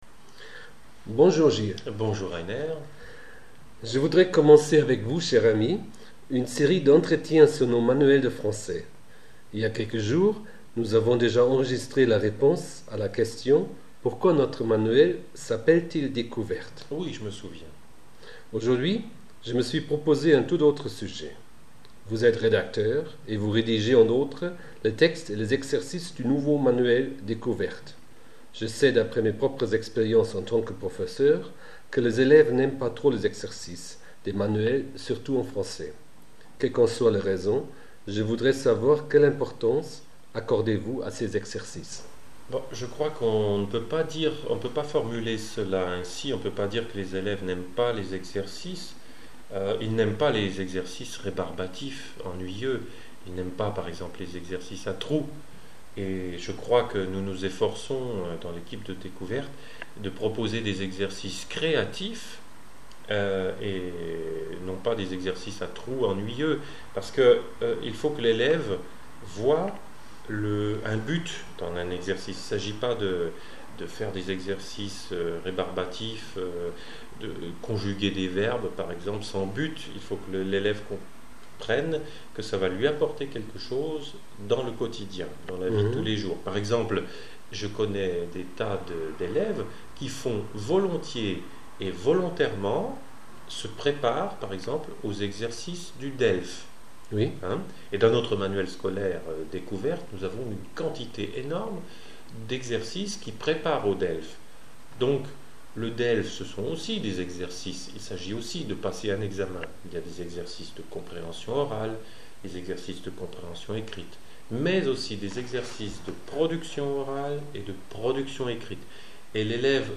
Les exercices des manuels de français